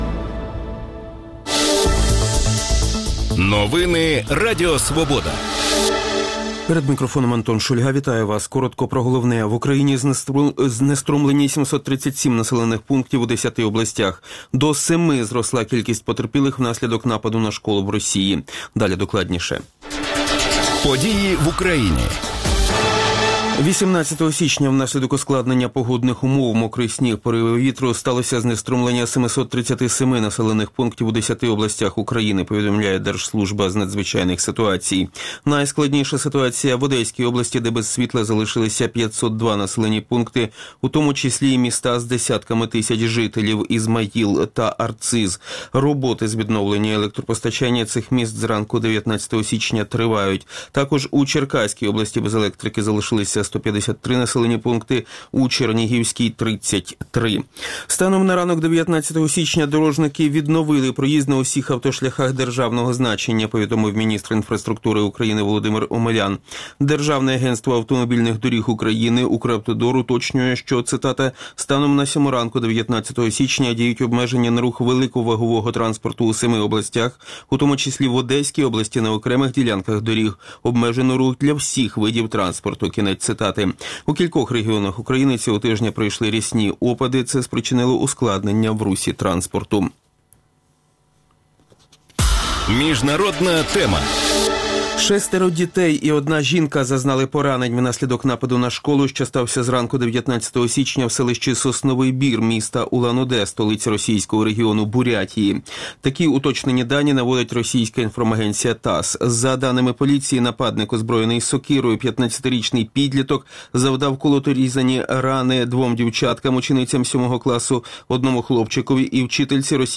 Про це у Ранковій Свободі говоритимуть міський голова Умані Олександр Цебрій та екс-міський голова Кам’янця-Подільського, екс-заступник міського голови Києва Олександр Мазурчак.